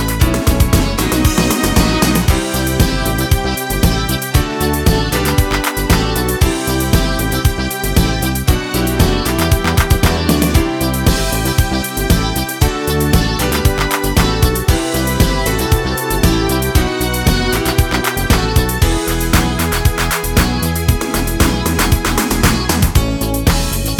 no Backing Vocals Disco 3:32 Buy £1.50